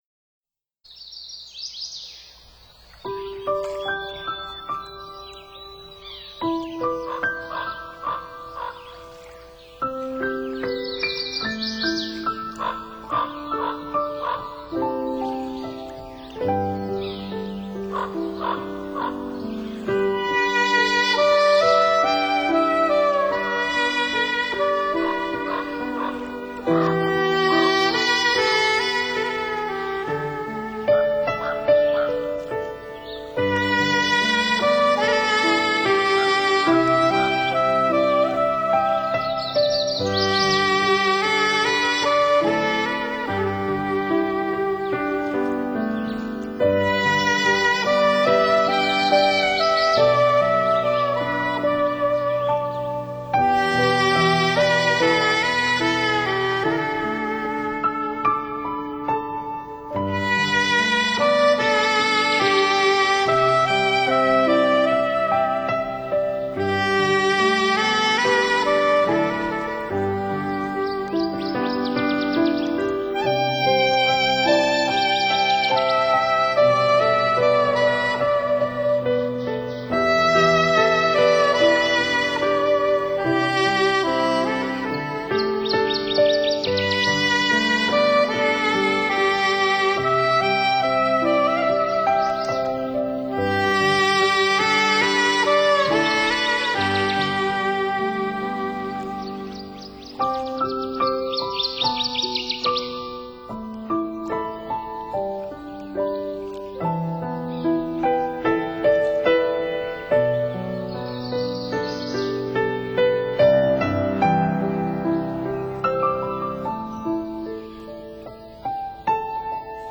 宗教音乐